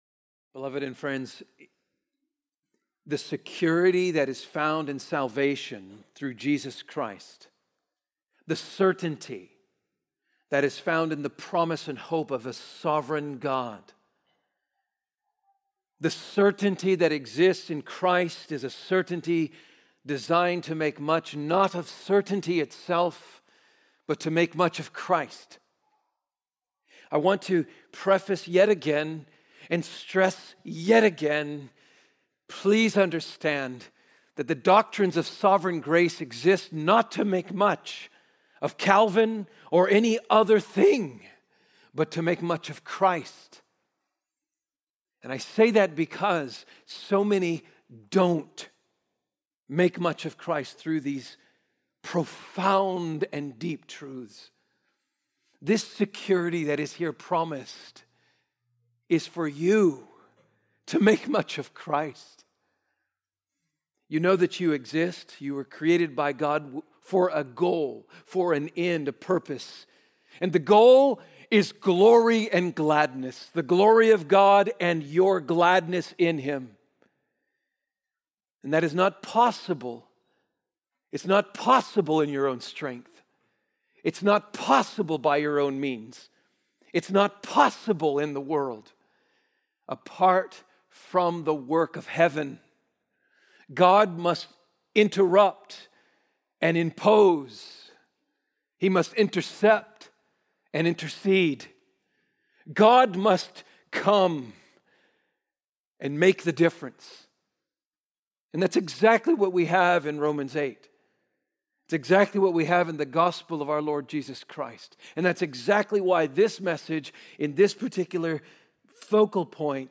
Sermons - Trinity Bible Church